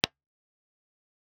Guitar Speaker IRs – Alpha Sonic Studio
Method: sine sweep
Samplerate and bit depth: 24bit/48kHz, unless audio interface sucks.
AKG_C414_SWEEP_IRHerunterladen
akg_c414_sweep_ir2.wav